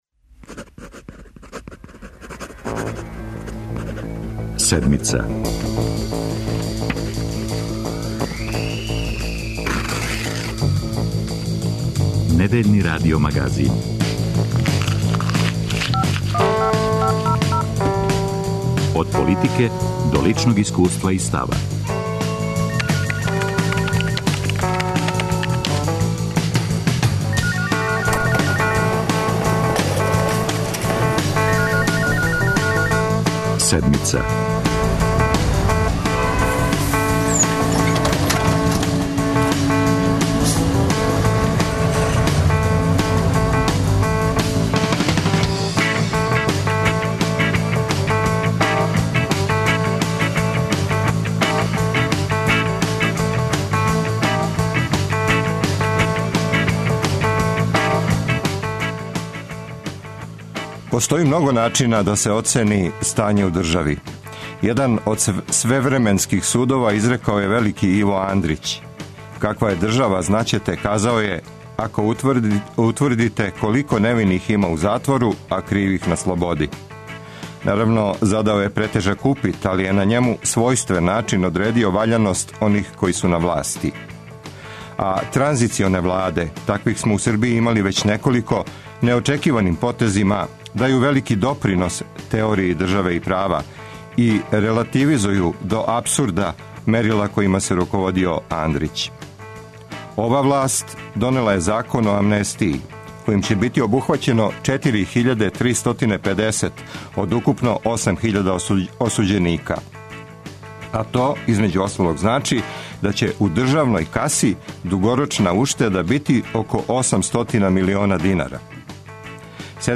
За Седмицу говоре аутори Закона, правни експерти, адвокати, полицајци, жртве амнестираних.